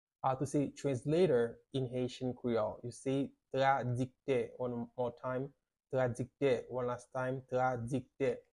How to say "Translator" in Haitian Creole - "Tradiktè" pronunciation by a native Haitian teacher
“Tradiktè” Pronunciation in Haitian Creole by a native Haitian can be heard in the audio here or in the video below:
How-to-say-Translator-in-Haitian-Creole-Tradikte-pronunciation-by-a-native-Haitian-teacher.mp3